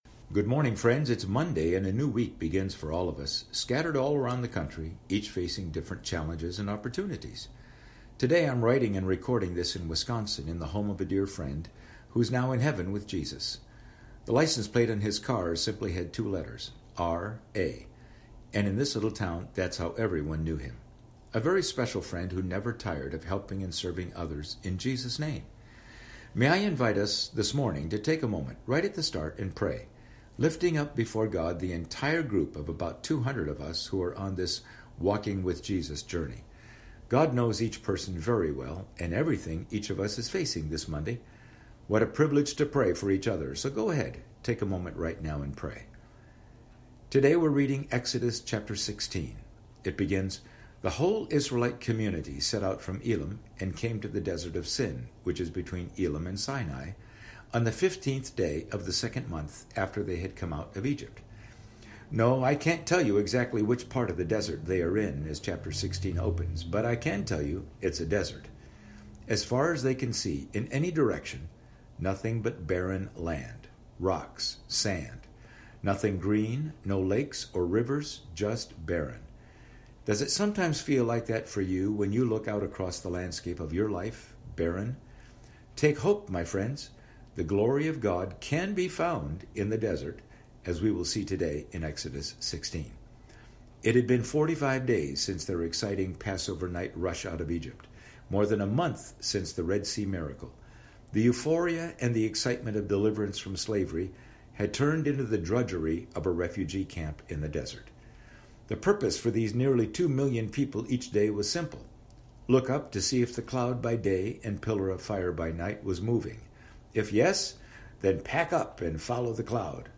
Today I'm writing and recording this in Wisconsin, in the home office of a dear friend who is now in heaven with Jesus.